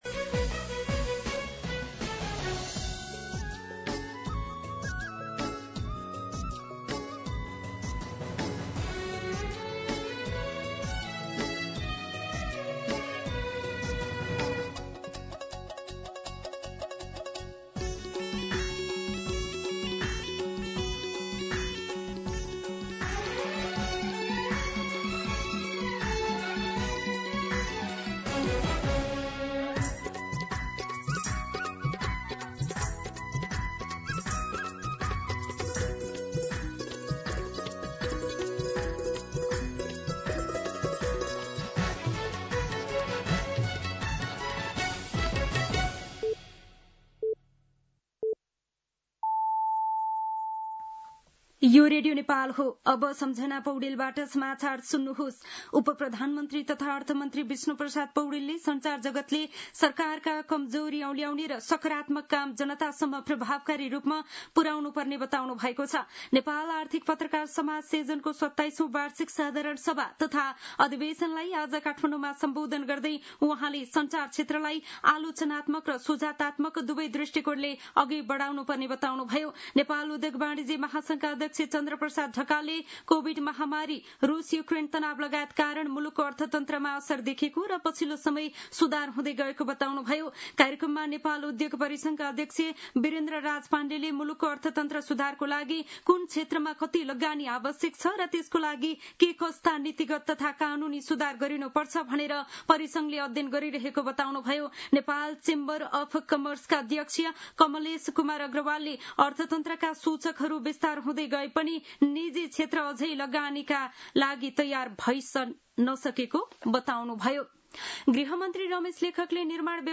मध्यान्ह १२ बजेको नेपाली समाचार : २१ भदौ , २०८२